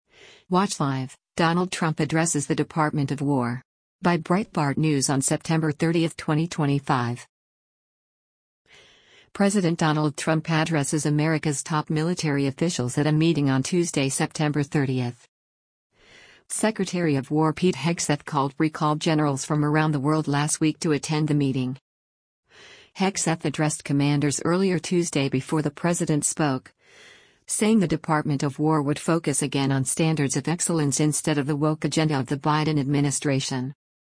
President Donald Trump addresses America’s top military officials at a meeting on Tuesday, September 30.